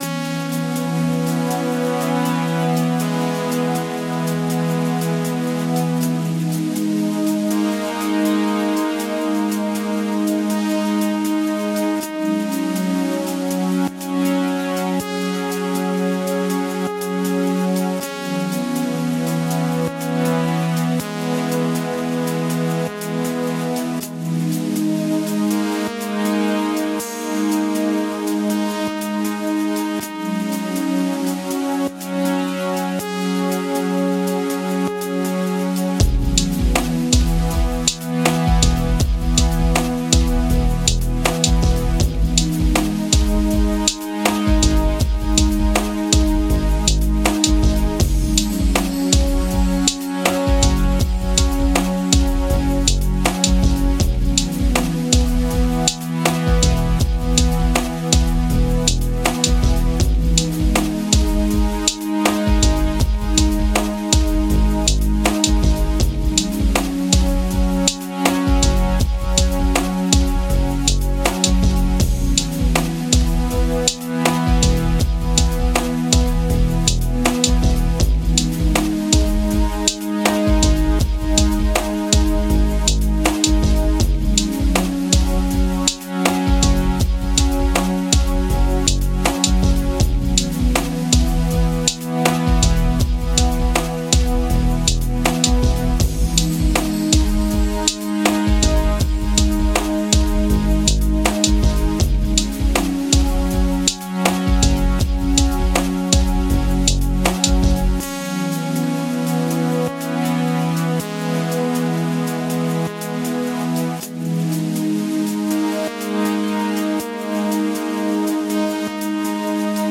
STRUMENTALE